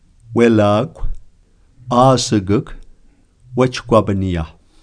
welakw_slow.wav